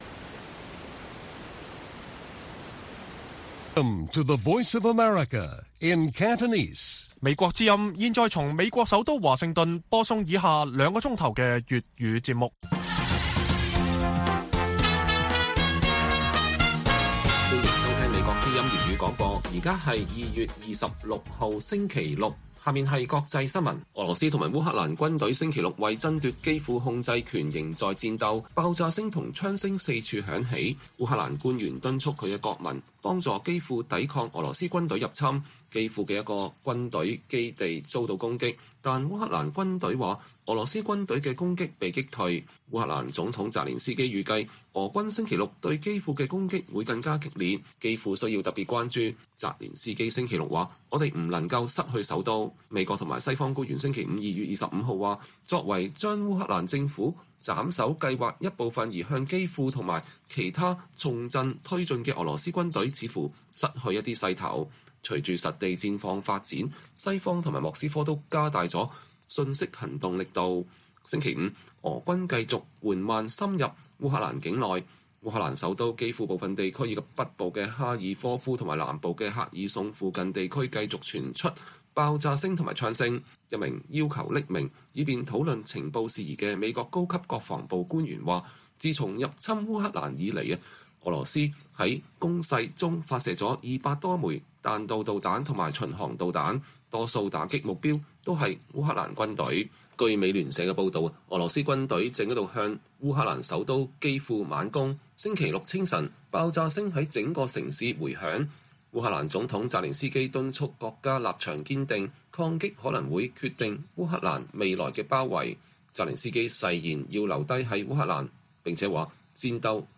粵語新聞 晚上9-10點 : 新書：尼克松訪華50年後 美國精英讓中國變得強大